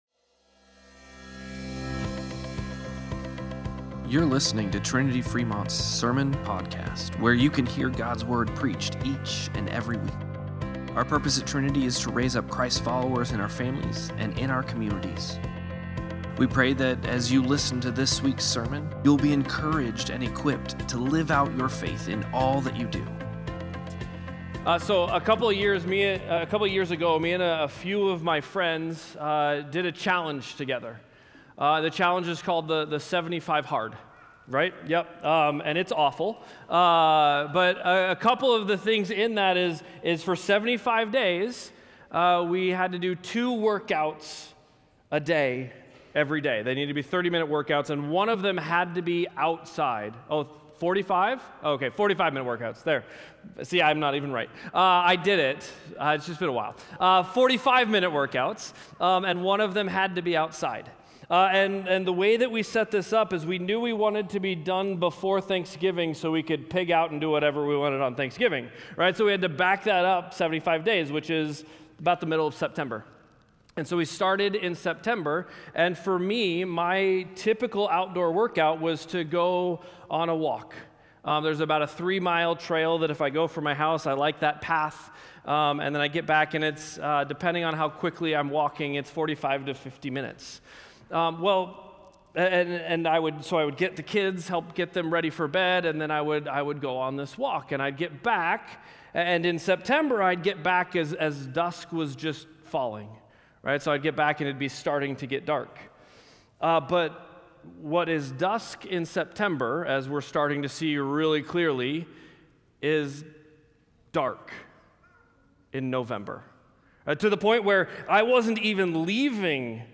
Sermon-Podcast-10-19.mp3